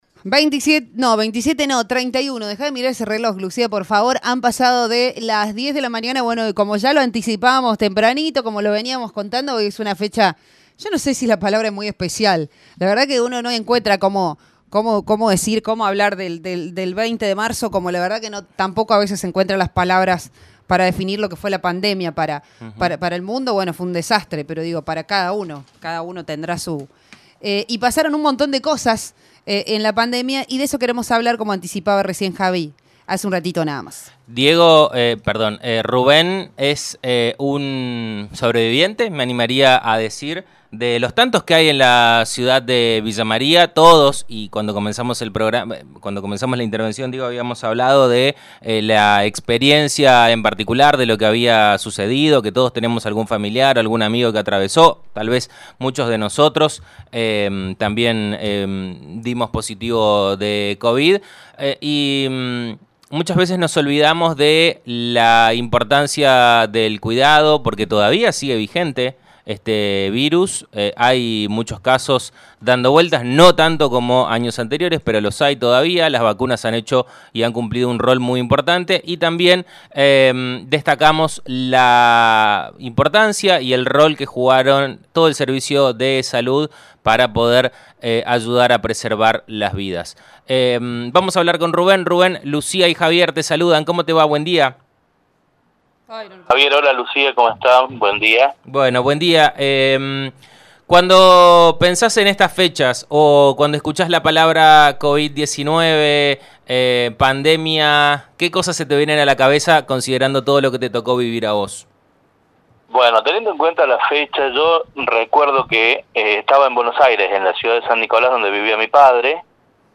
A 3 años del dictado de la cuarentena: historia de vida en primera persona de un sobreviviente del virus – Radio Show Villa María